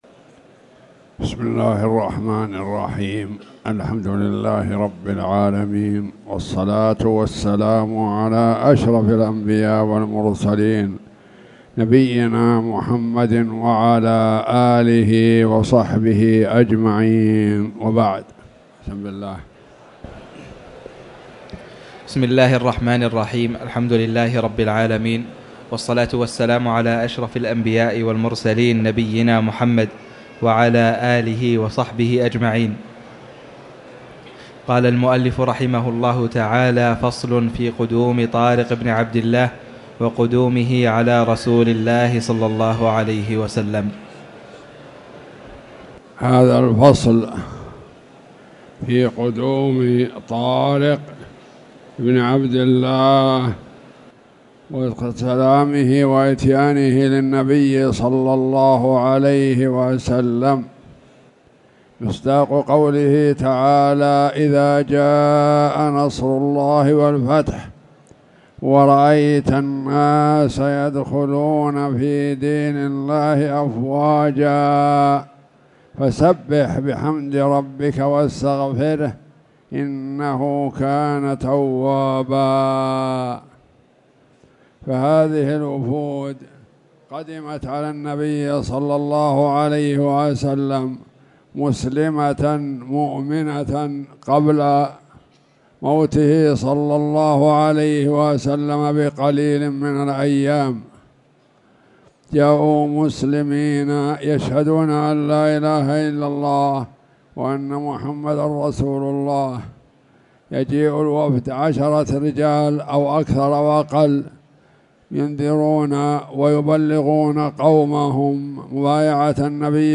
تاريخ النشر ٢٤ رجب ١٤٣٨ هـ المكان: المسجد الحرام الشيخ